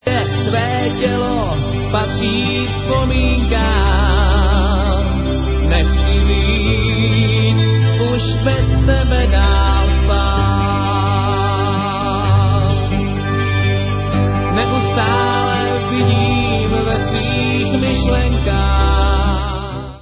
skvělými pomalými hity